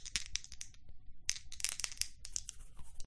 shakedice.mp3